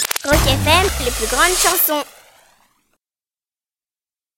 La voix off ROC FM
Liner-6-Peche.mp3